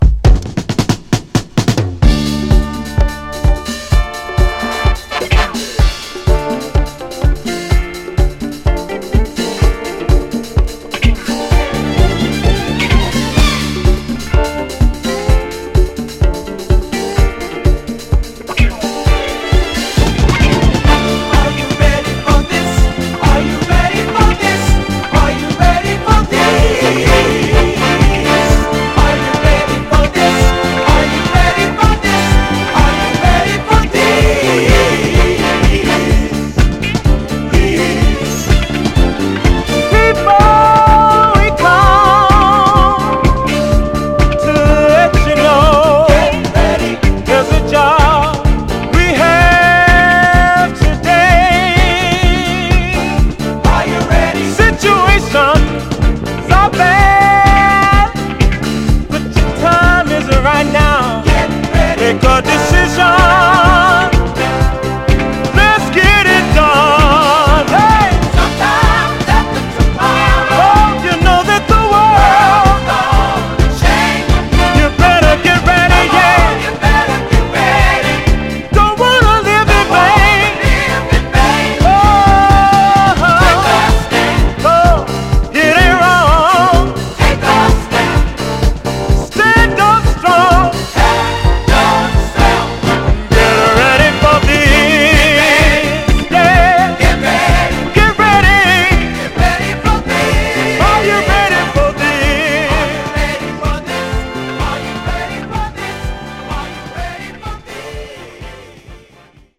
文句なしに最高なフィリー・ダンサーです！
ガラージ・スタイルの名ディスコ・クラシックでもあります。
盤はエッジ中心に細かいスレ、細かいヘアーラインキズ箇所ありますが、グロスが残っておりプレイ良好です。
※試聴音源は実際にお送りする商品から録音したものです※